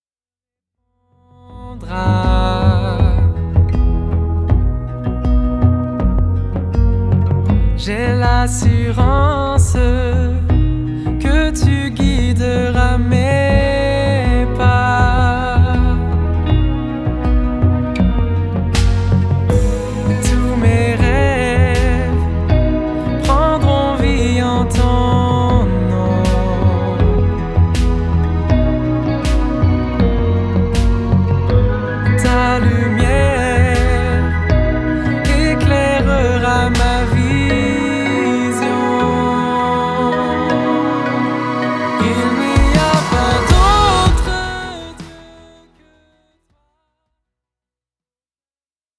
chants de louange aux accents électro